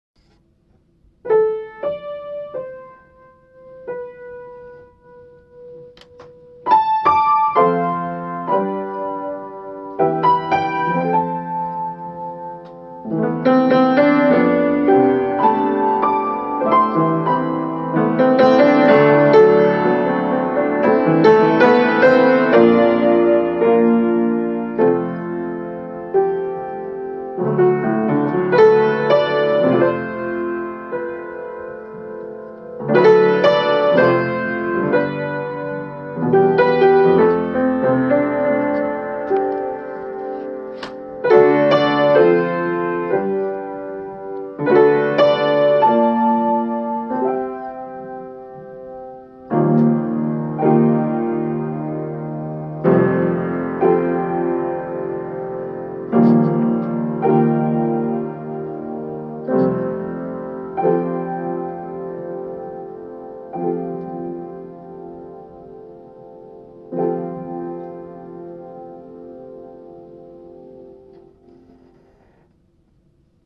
Violin and piano duet; incidental music for straight play.